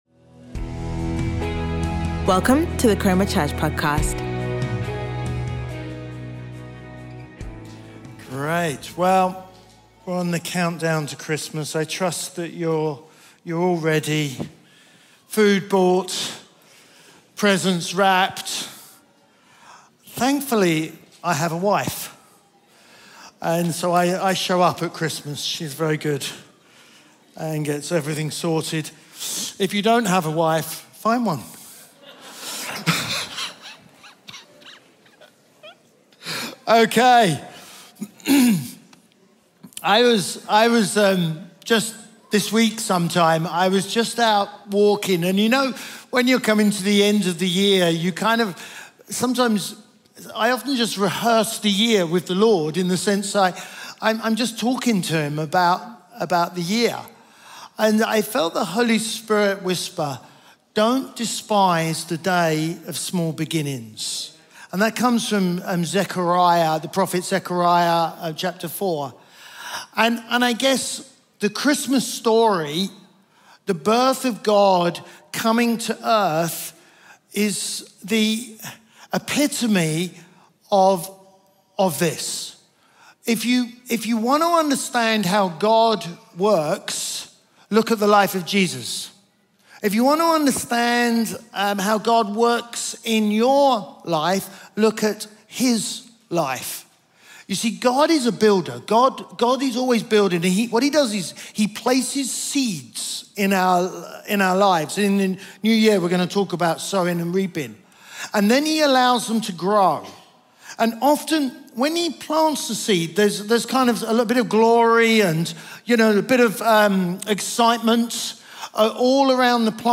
Sunday Sermon Small Beginnings